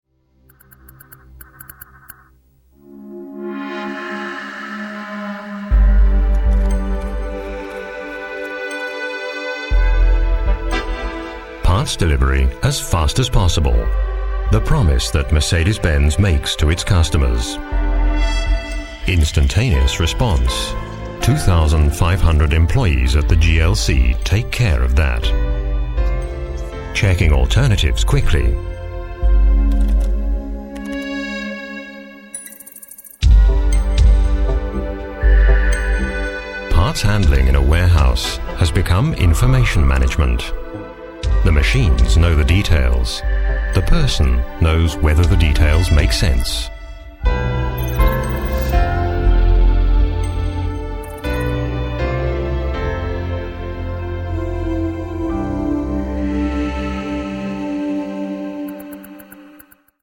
Deutsch spricht er mit leichtem englischem Akzent.
Sprecher englisch uk.
Sprechprobe: eLearning (Muttersprache):
voice over artist english (uk)